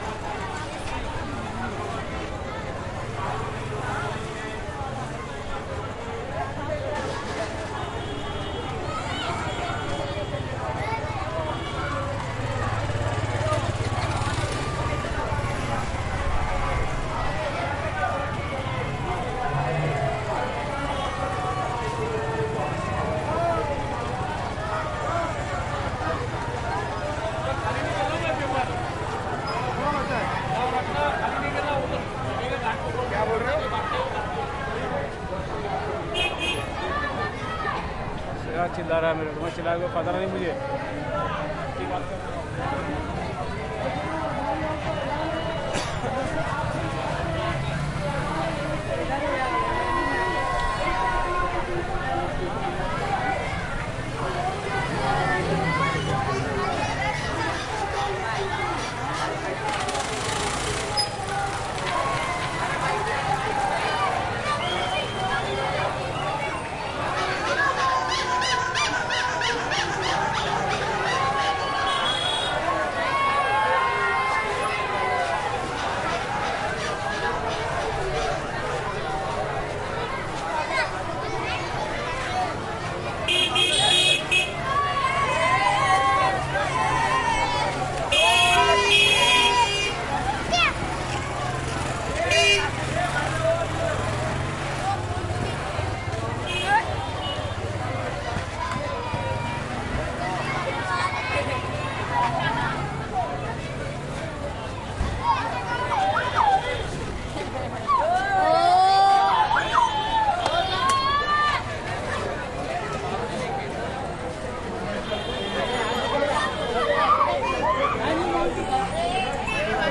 印度 " 交通繁忙的卡车，汽车，摩托车，人力车的喇叭声，阴霾的运动+一些附近的人和锤子的印度。
描述：交通沉重的喉咙卡车汽车摩托车人力车喇叭鸣笛运动+一些附近的人和锤击India.flac
标签： 运动 交通 喇叭 人力车 印度 汽车 honks 卡车 摩托车 嘶哑
声道立体声